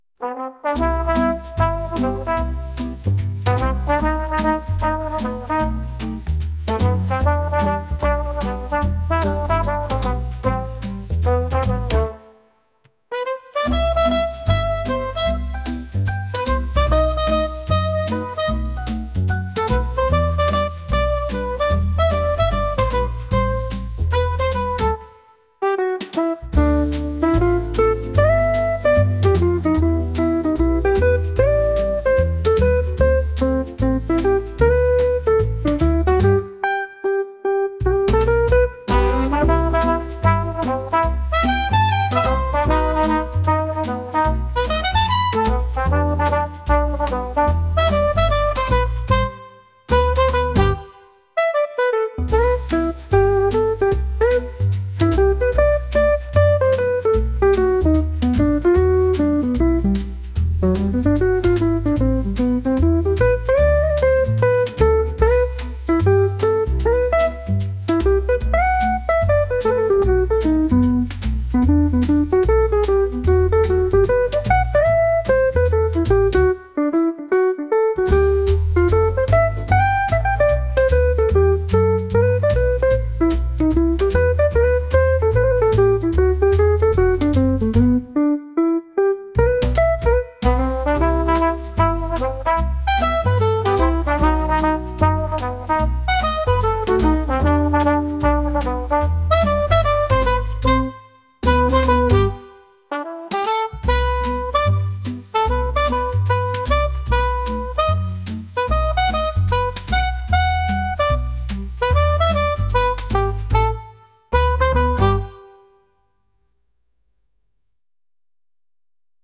ハンバーガー片手に街中を散歩するような曲です。